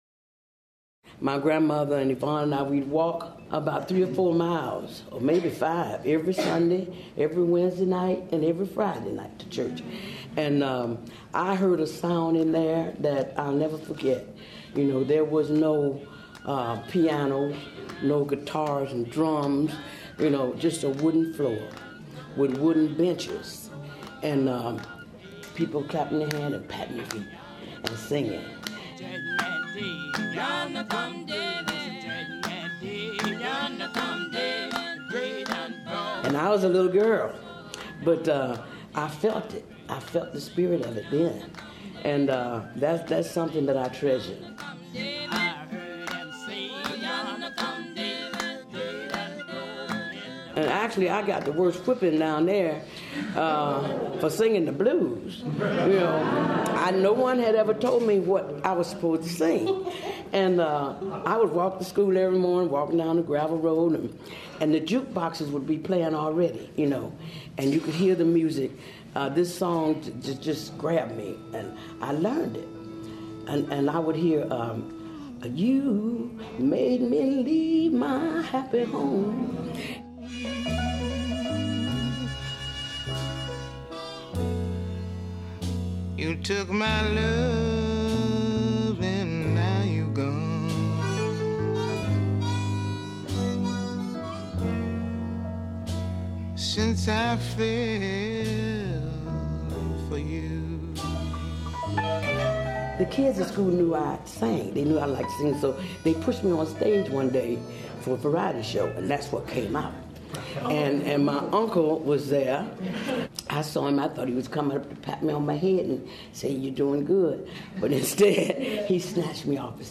The event was held at the Jane Addams Hull-House Museum and was recorded by Chicago Amplified, a program of Chicago Public Media.
Featured music:
“O Day” by Bessie Jones, from “The Alan Lomax Collection: Southern Journey, Vol. 1 – Voices from the American South.”
“Since I Fell For You” by Nina Simone, from “The Soul of Nina Simone.”